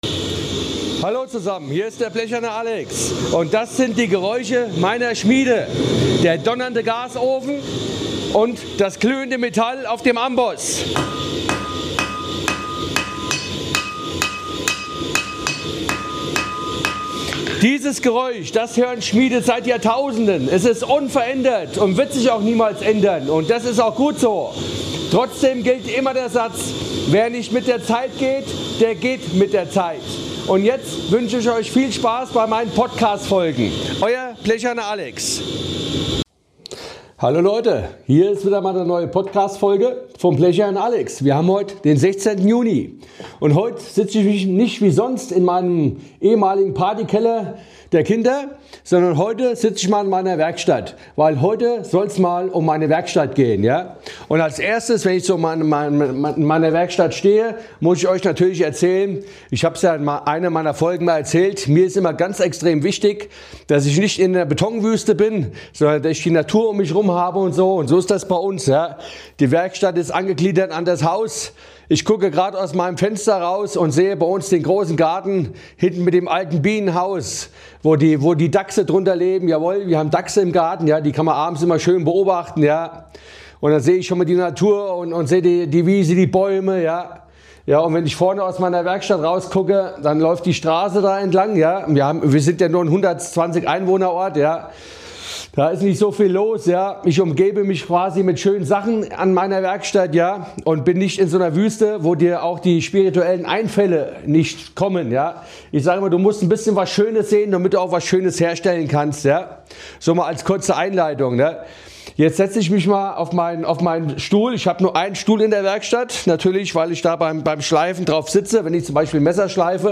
In dieser Folge sitze ich in meiner Schmiede und erzähle über sie und erkläre warum mein Amboss und Ich verbunden sind!!! Eine besondere Werkstatt und die besonderen Menschen die sie sehen dürfen.